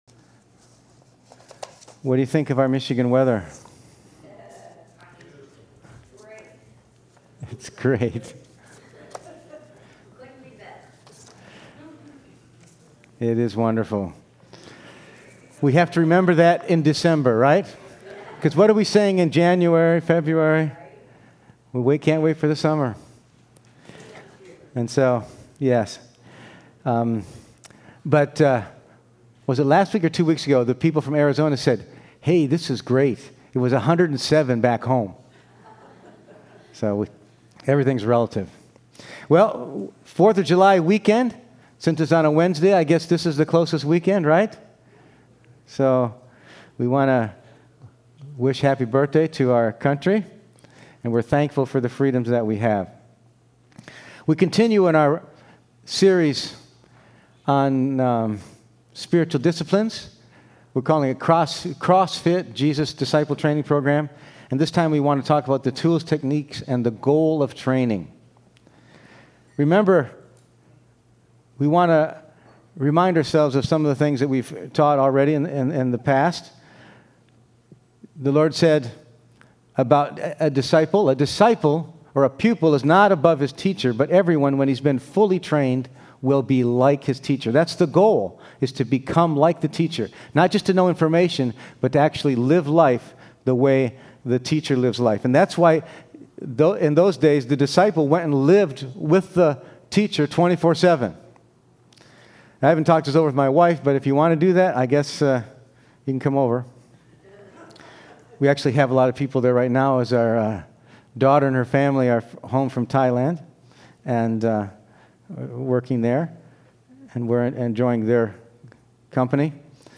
Passage: 2 Peter 1:3-15, Galatians 5:13-26 Service Type: Sunday Morning